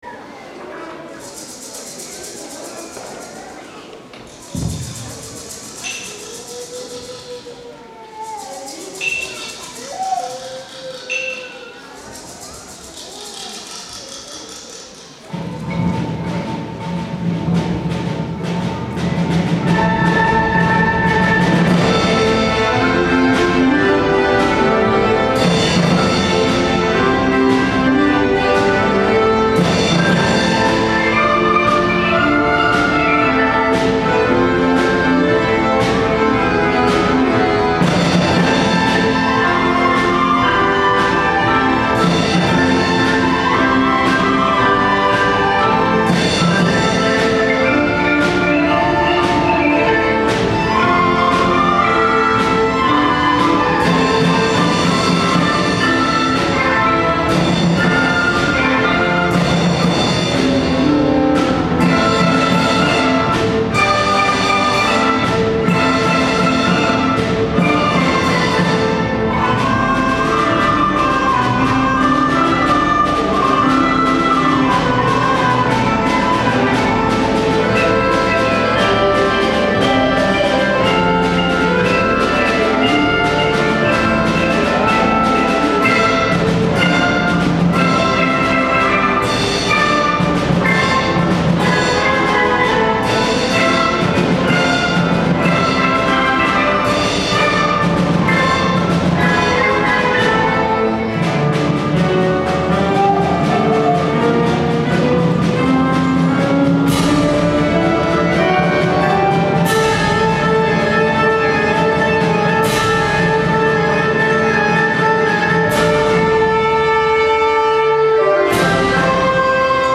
いよいよ本番。海賊船が近づいてくるような怪しい音が・・・。
音をパッと止めるところはかっこよく、ゆったりしたところは船が進んでいくように表現するなど、下の学年の子どもたちから憧れの存在であるリーダー・サブリーダーの見事な演奏でした。